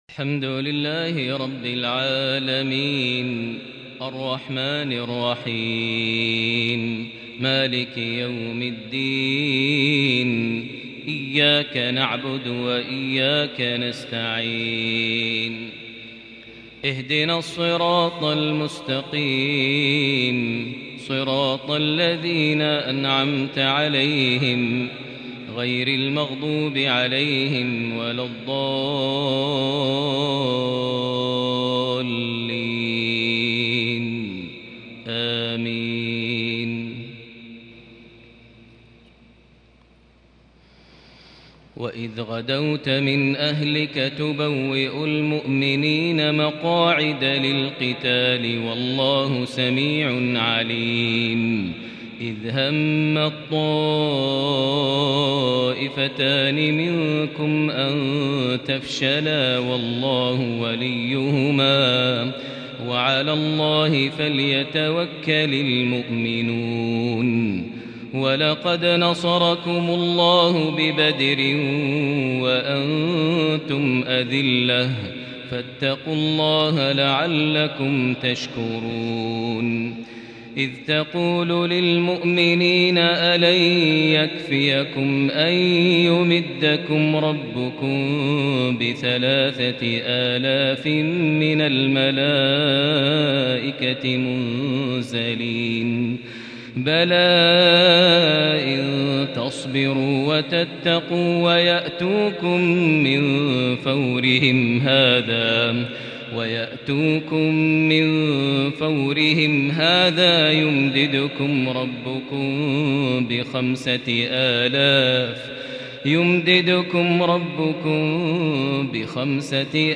تلاوة هادئه لصلاة الفجر ٢٤ شوال ١٤٤١هـ سورة آل عمران١٢١- ١٣٦ > 1441 هـ > الفروض - تلاوات ماهر المعيقلي